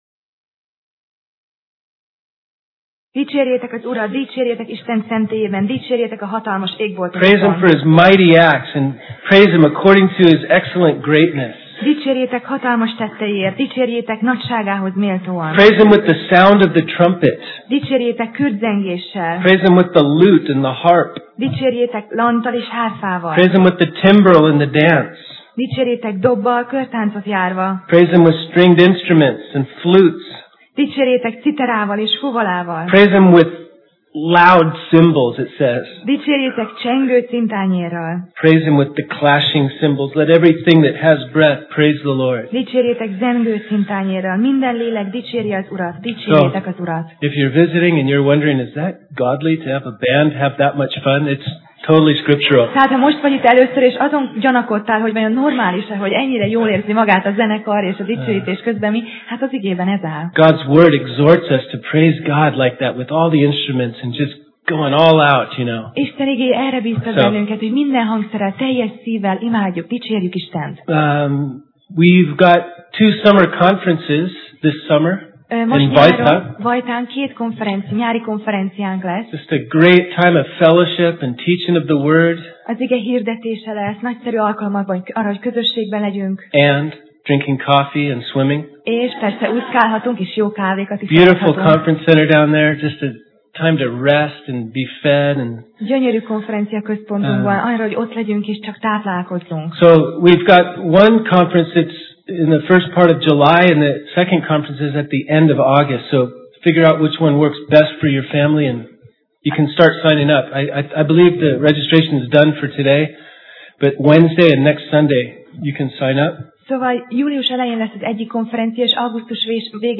Tematikus tanítás Passage: Róma (Romans) 6:17 Alkalom: Vasárnap Reggel